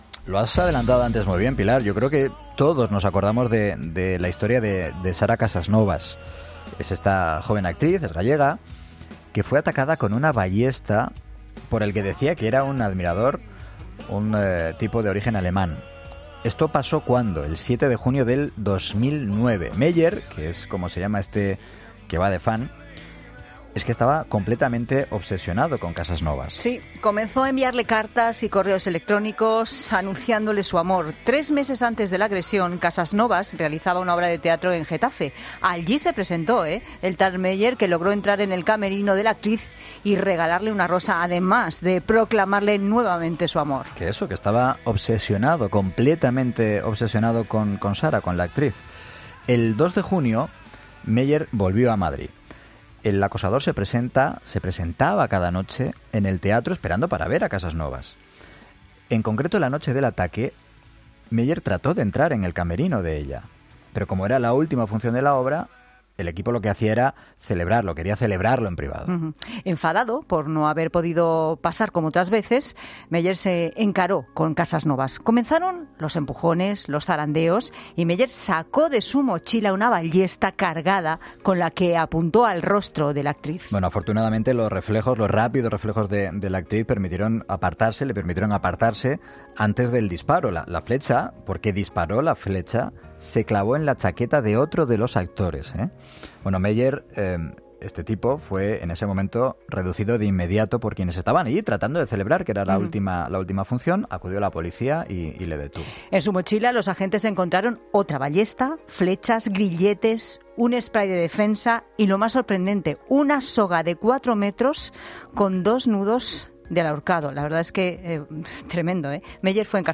Carlos Castresana, abogado de la actriz Sara Casasnovas, en 'Mediodía COPE'